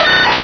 Cri de Sabelette dans Pokémon Rubis et Saphir.